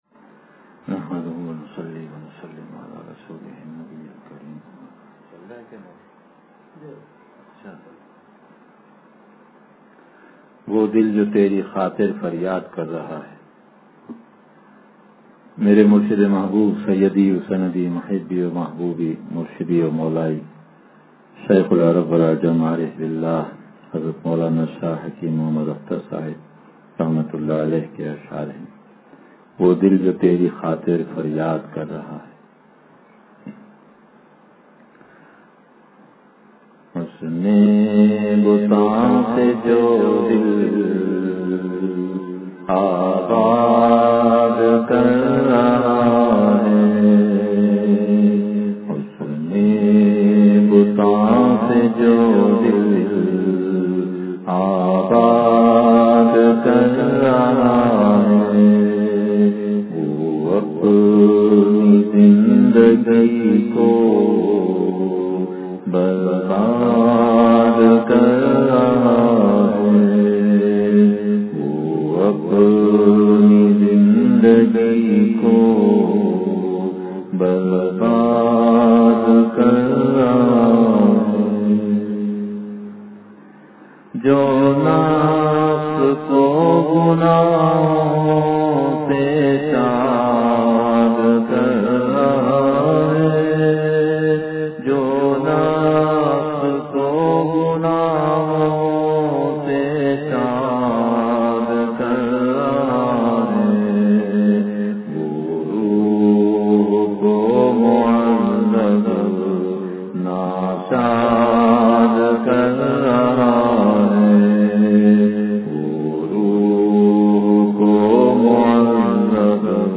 وہ دل جو تیری خاطر فریاد کر رہا ہے – ختم قرآن کریم کے موقع پر بیان